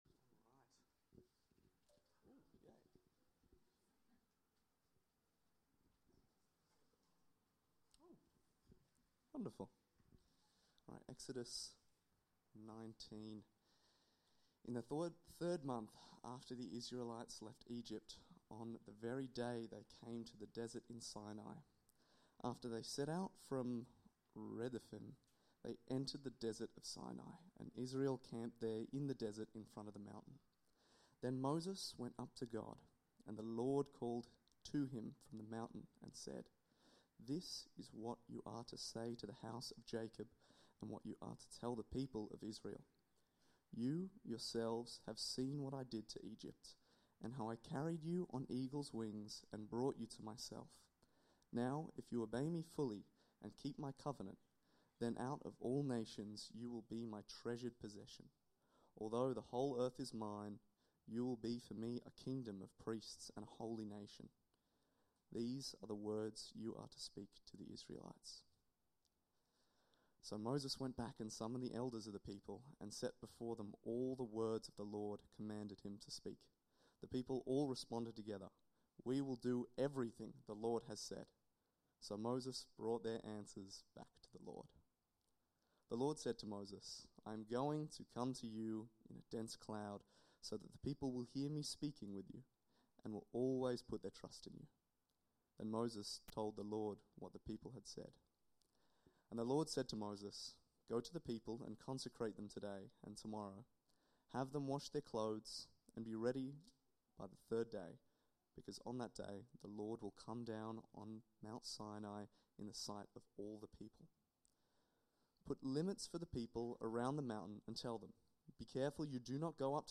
Sermons | Church At The Gabba